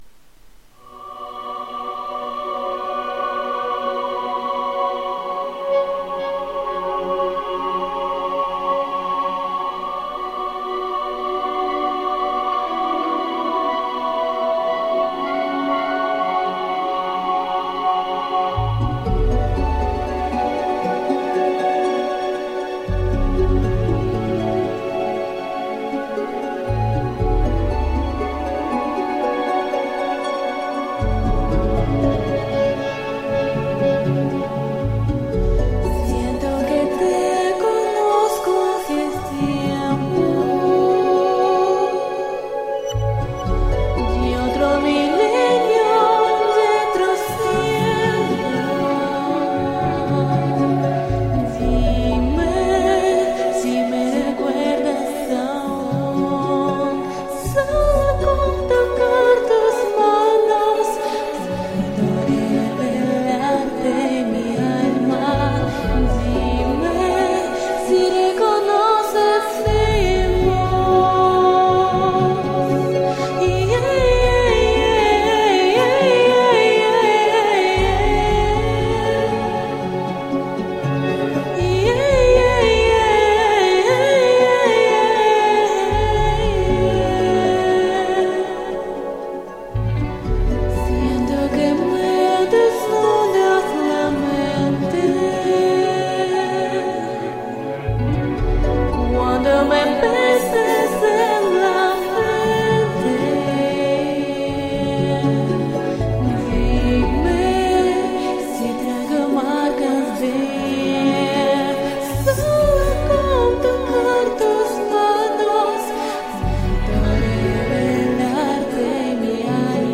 Комментарий инициатора: Очень красивая и мелодичная песня!
звучание у нее было ярче и выразительнее.